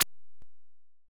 fwip.wav